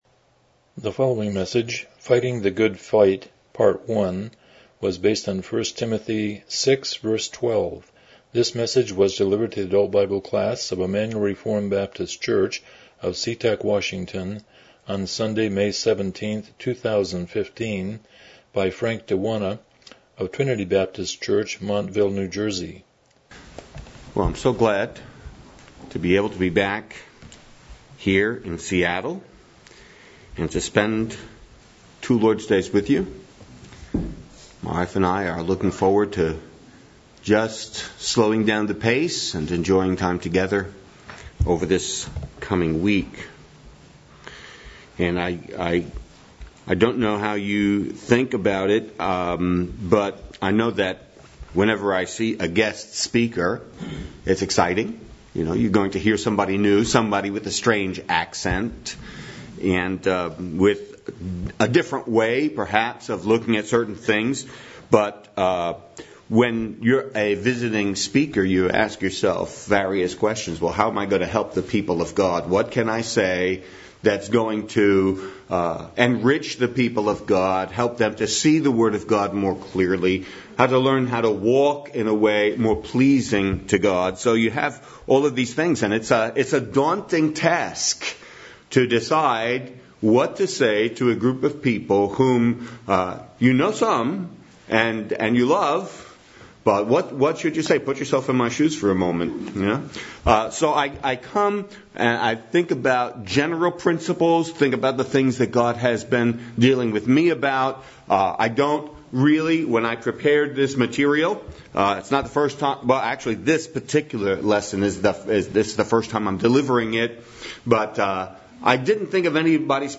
Miscellaneous Passage: 1 Timothy 6:12 Service Type: Sunday School « The Prayer of an Intercessor